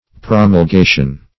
Promulgation \Pro`mul*ga"tion\, n. [L. promulgatio: cf. F.